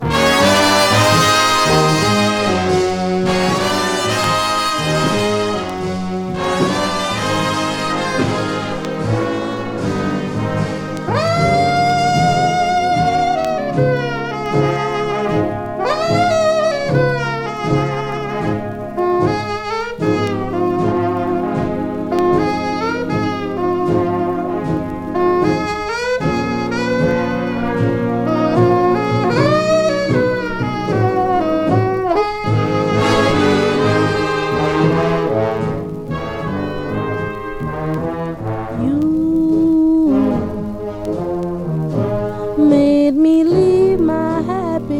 どれもギラギラとした豪快かつダンサブルなナンバーがたっぷりの構成が嬉しい1枚です。
Jazz, Blues, Jump Blues, Swing　USA　12inchレコード　33rpm　Mono